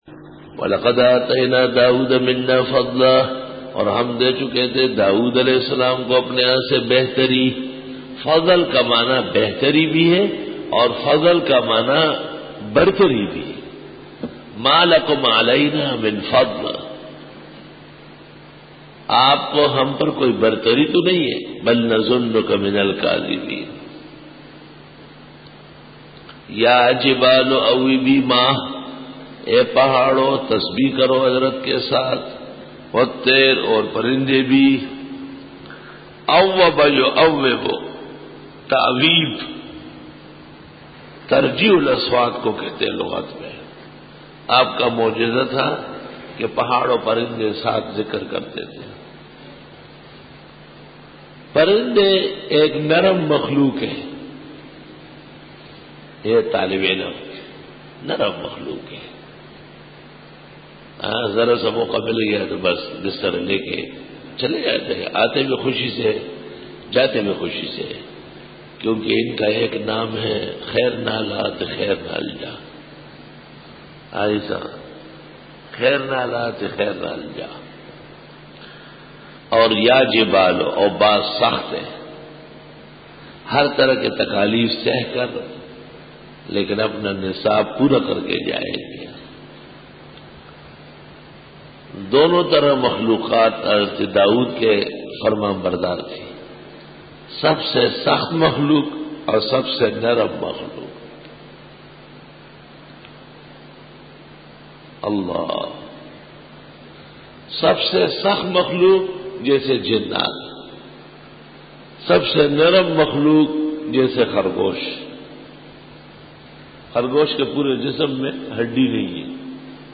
Dora-e-Tafseer 2006